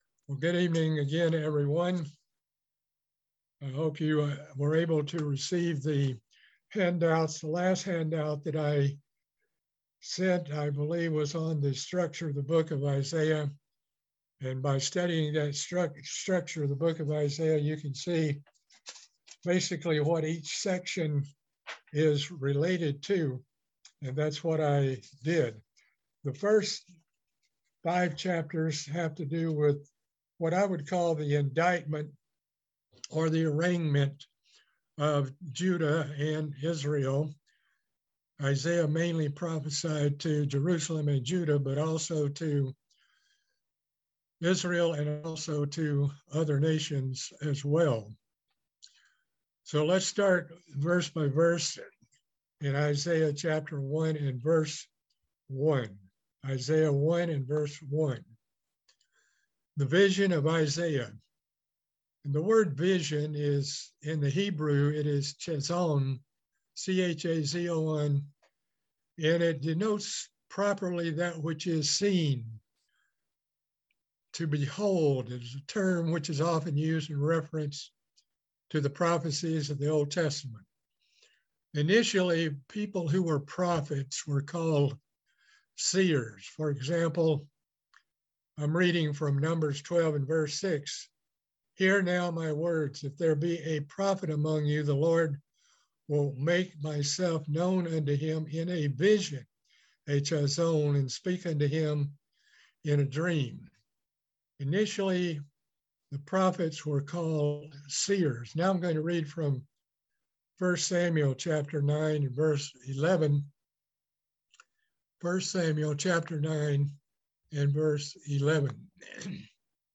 Book of Isaiah Bible Study - Part 2